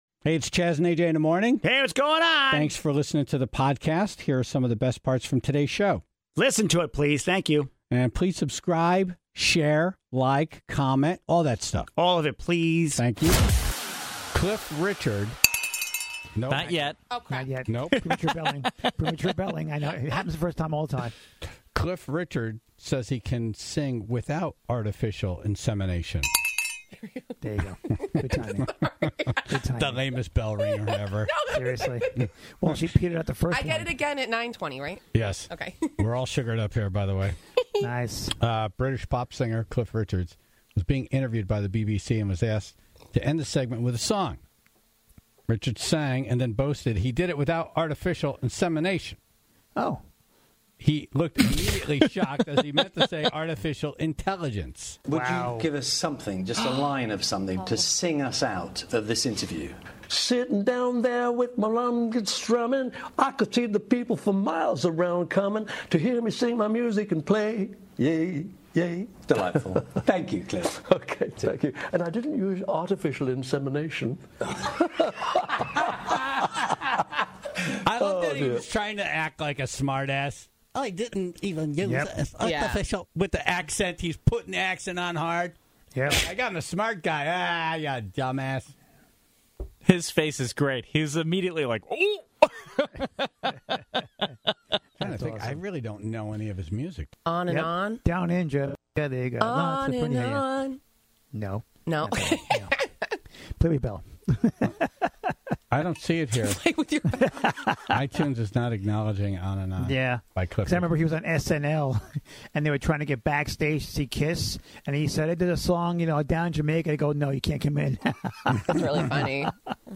So, the guitar playing paramedic was in studio this morning to tell us why you should not throw milk on a person having a heart attack.